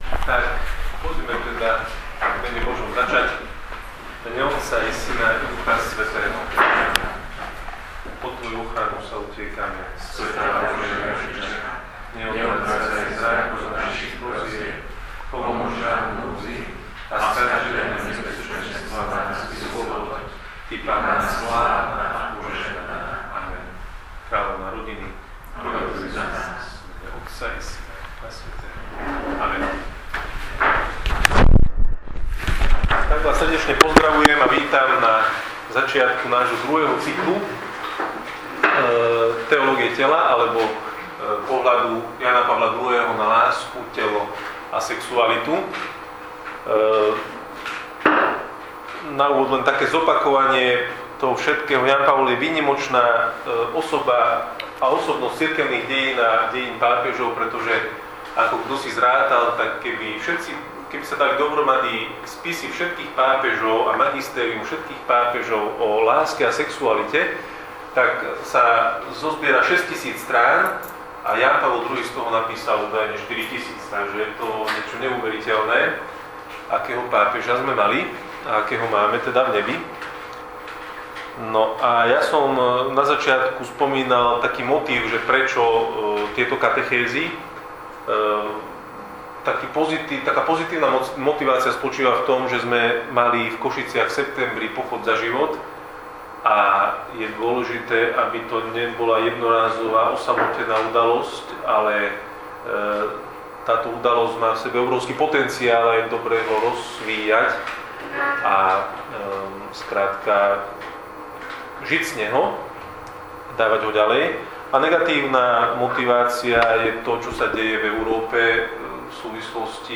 Prednáška 1- zvukový záznam -TU, - obrazový materiál -TU